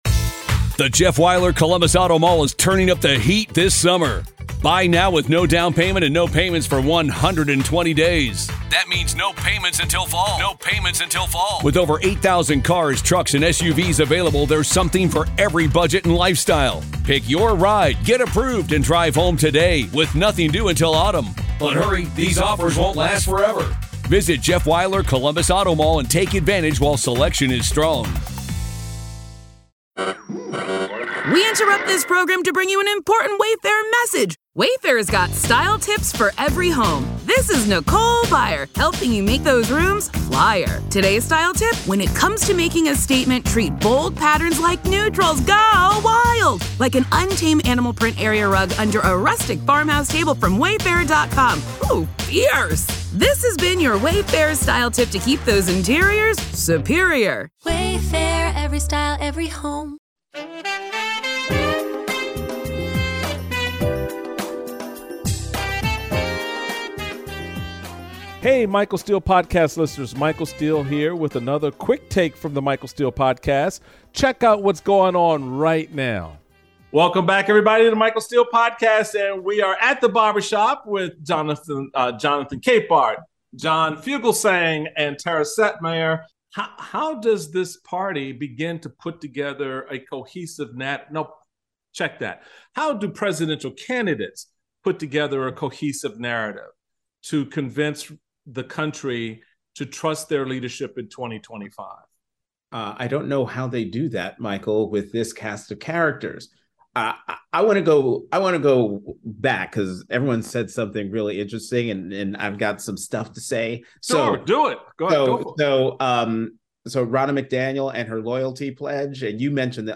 Michael is joined by Jonathan Capehart, John Fugelsang and Tara Setmayer for a conversation on all kinds of politics and crazy, touching on Ronna McDaniel's "loyalty oath," the Fox News-Dominion lawsuit and predict...